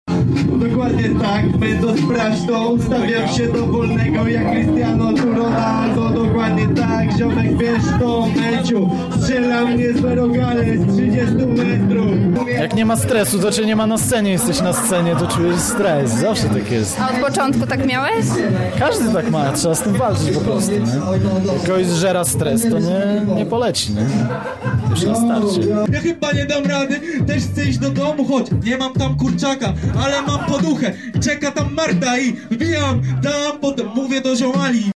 O swoich wrażeniach mówi jeden z uczestników muzycznych rozgrywek.